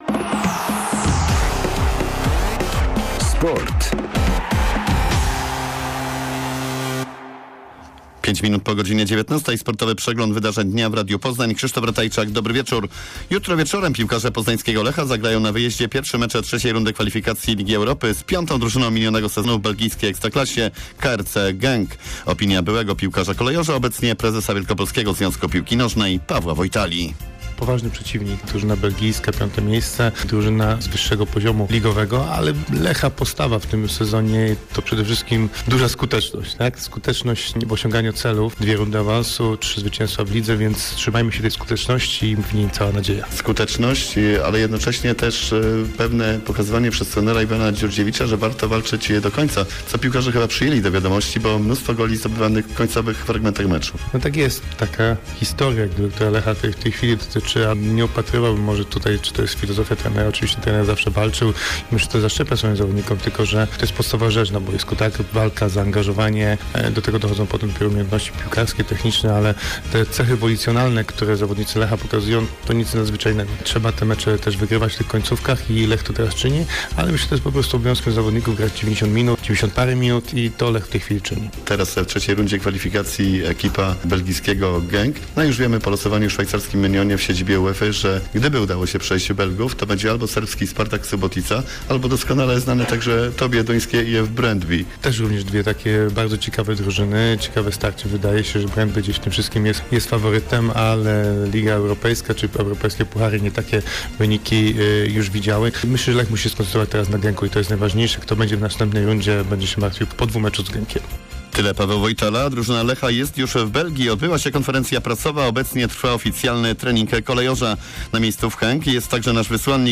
08.08 serwis sportowy godz. 19:05
W środowym serwisie relacja na żywo naszego wysłannika z Genk, gdzie piłkarze Lecha zagrają w III rundzie eliminacji Ligi Europy. Ponadto świeże meldunki z ME w lekkiej atletyce i TdP.